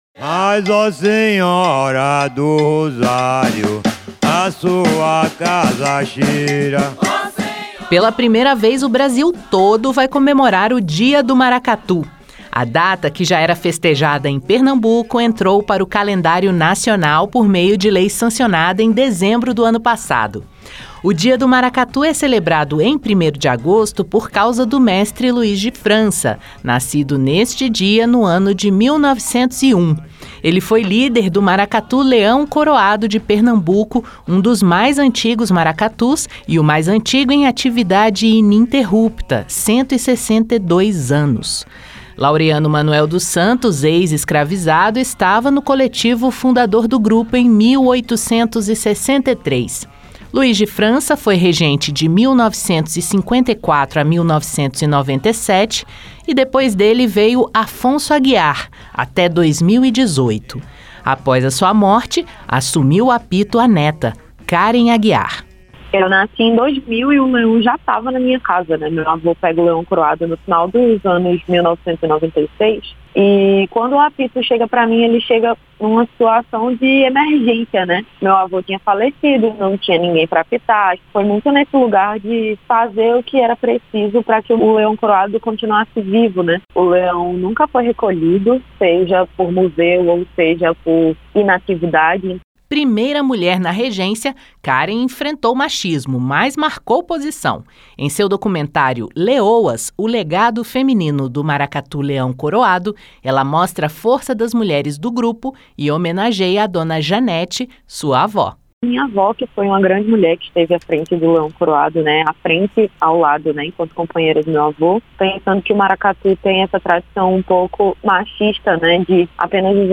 Trilha Sonora